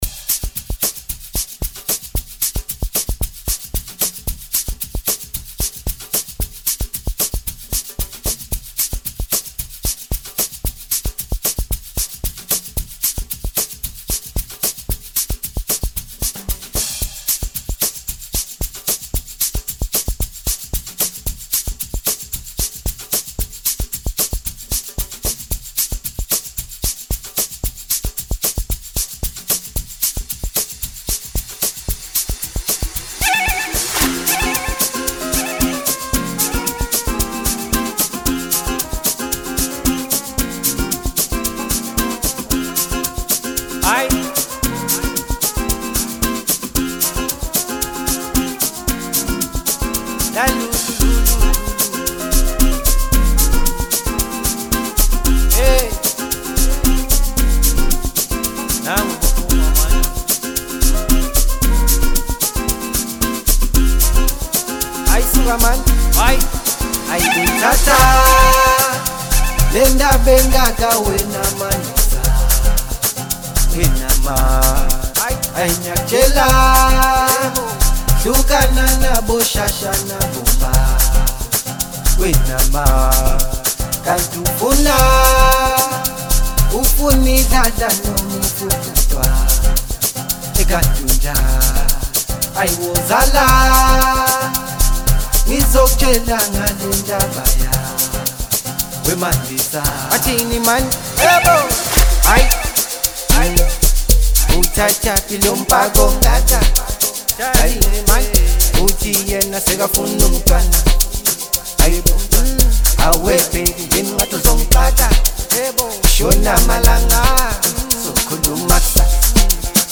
Amapiano songs